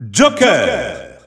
The announcer saying Joker's names in French.
Joker_French_Announcer_SSBU.wav